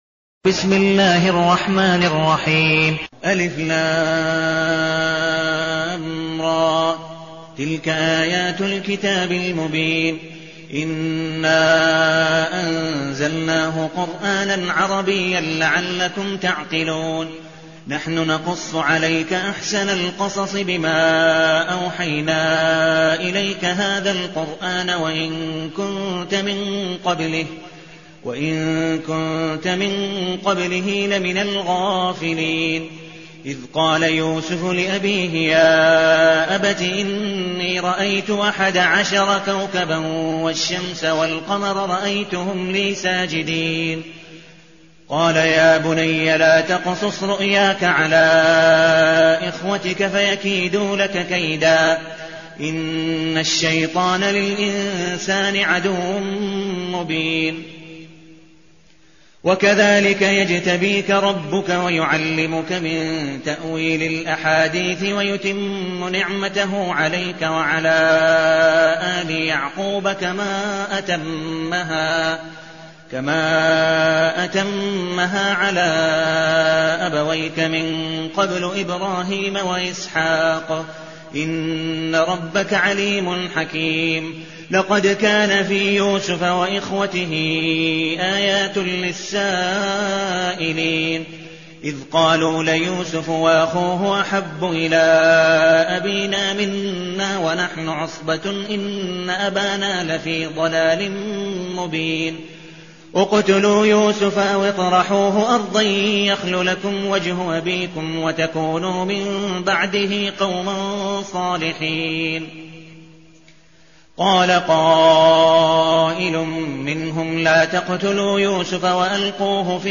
المكان: المسجد النبوي الشيخ: عبدالودود بن مقبول حنيف عبدالودود بن مقبول حنيف يوسف